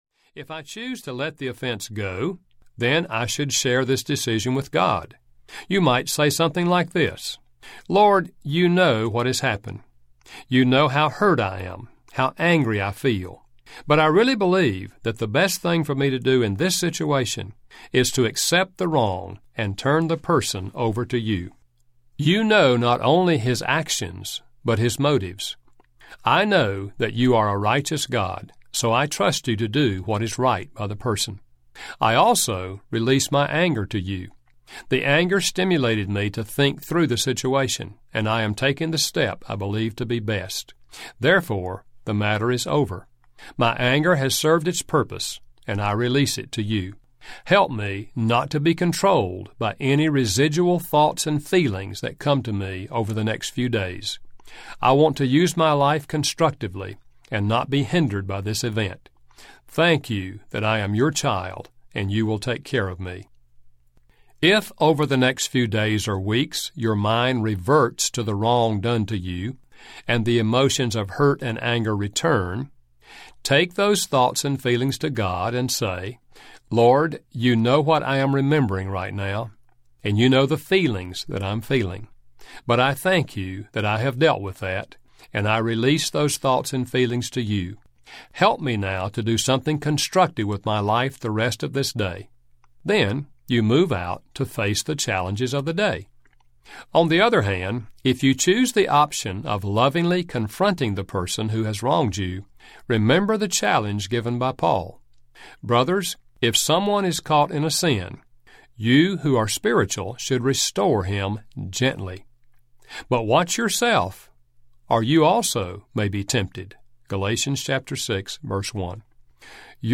Anger Audiobook
Narrator
Dr. Gary Chapman
6 Hrs. – Unabridged